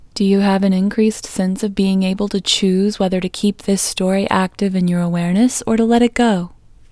OUT Technique Female English 26